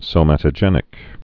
(sō-mătə-jĕnĭk, sōmə-tə-) also so·mat·o·ge·net·ic (-jə-nĕtĭk)